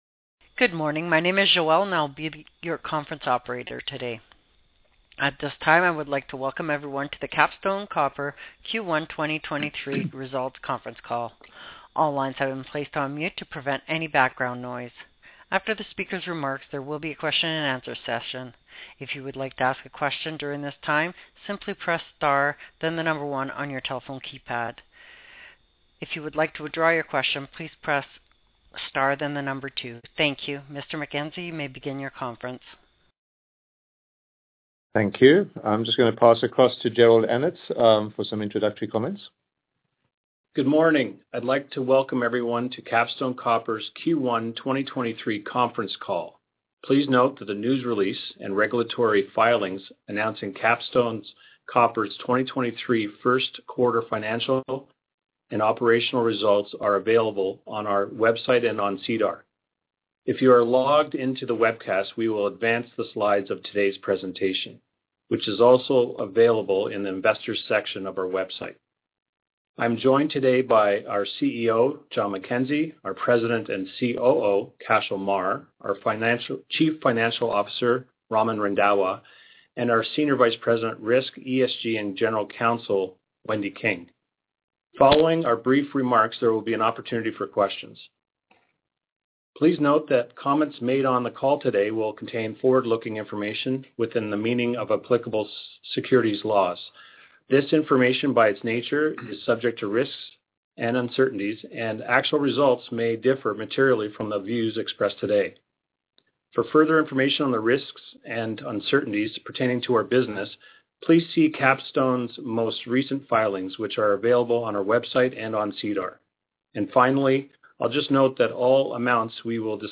2023-Q1-Results-Conference-Call-Audio-File.mp3